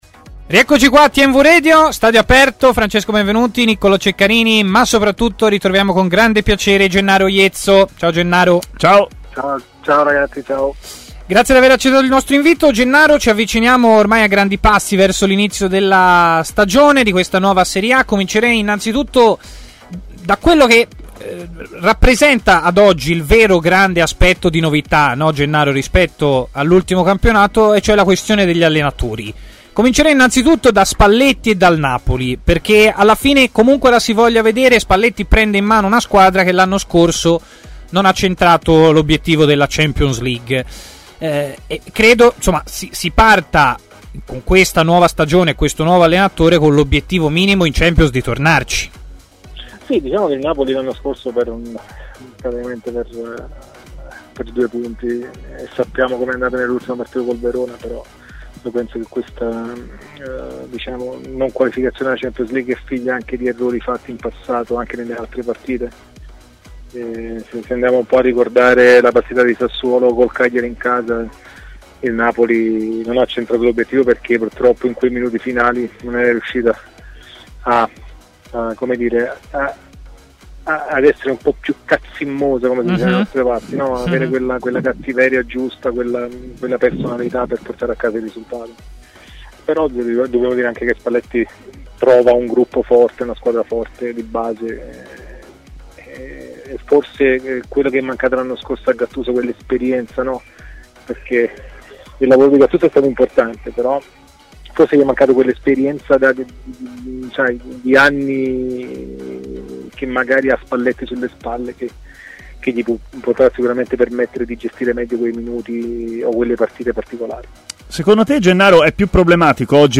Le Interviste